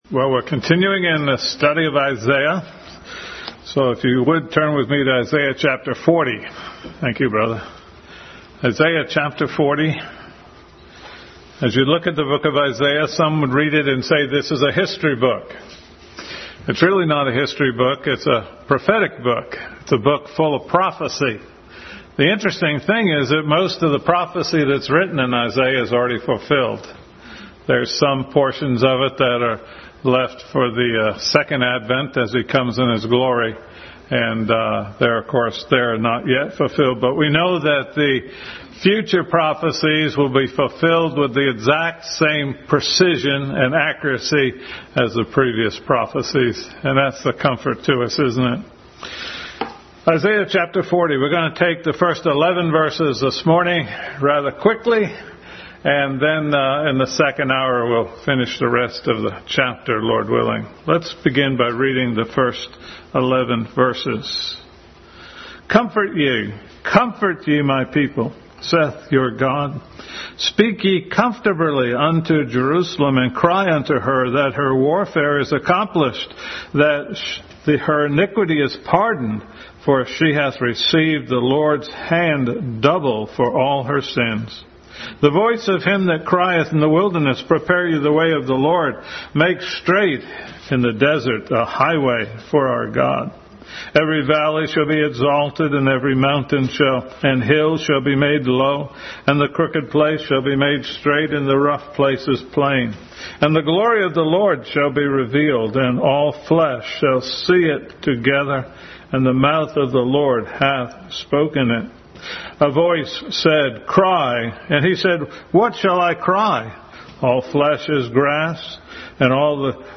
Adult Sunday School Class continued study of Christ in Isaiah.